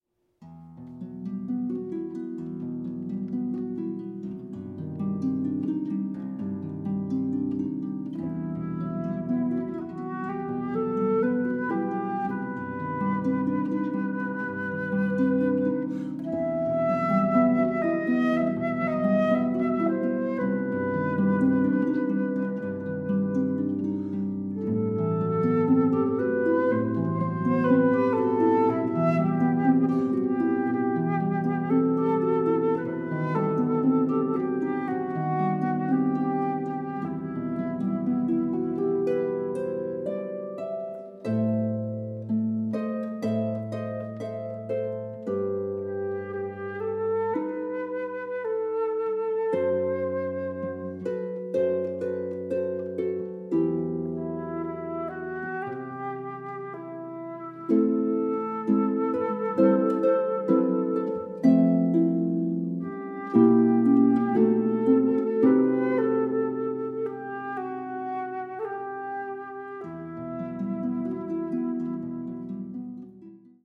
arpa